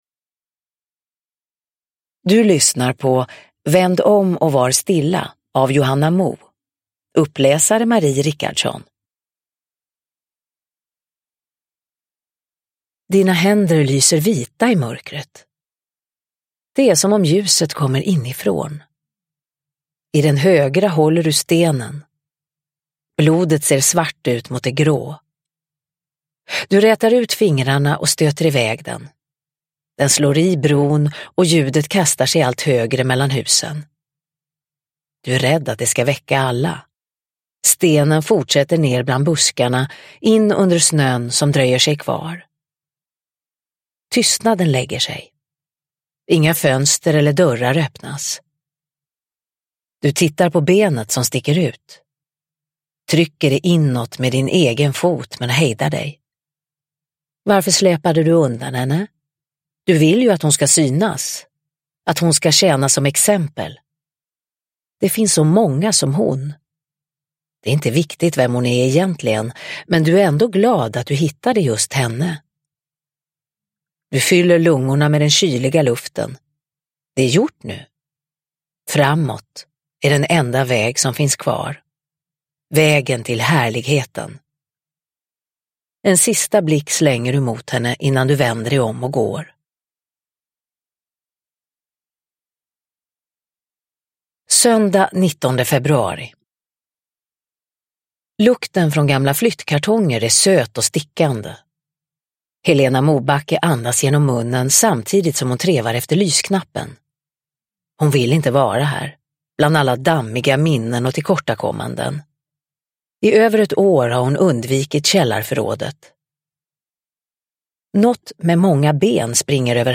Uppläsare: Marie Richardson
Ljudbok